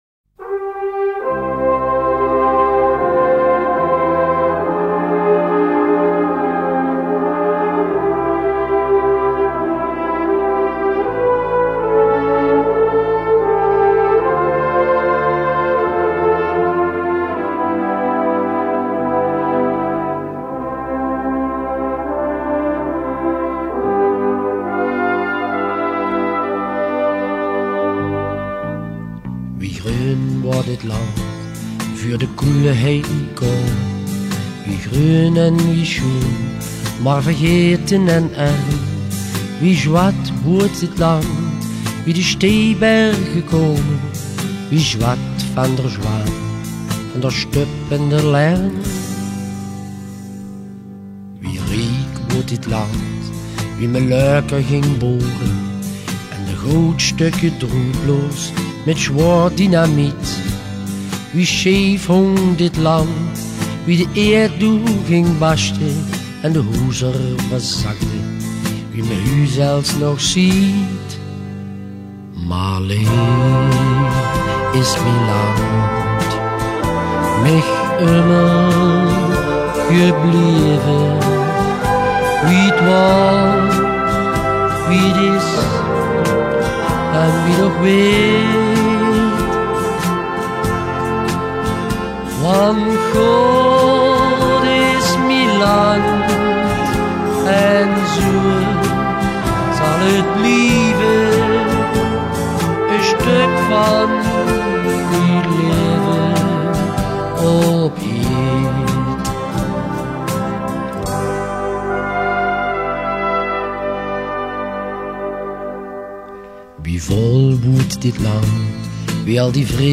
Geleens Mannenkoor Mignon